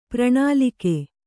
♪ prṇālike